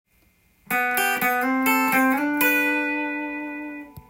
エレキギターで弾ける【Gミクソリディアンフレーズ集】オリジナルtab譜つくってみました
ロックミュージシャン誰でも使うコードトーンとミクソリディアンスケール
を混ぜた定番過ぎるフレーズです。